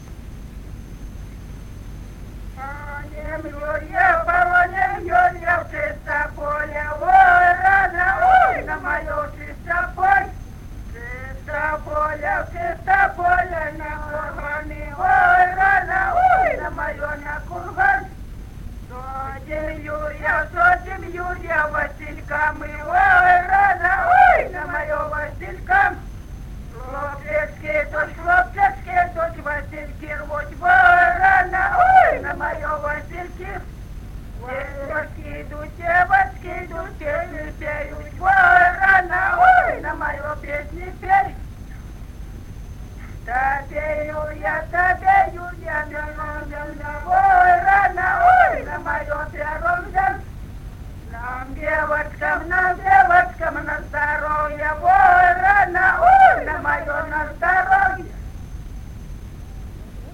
Народные песни Стародубского района «Погоним Юрья», юрьевская таночная.
с. Курковичи.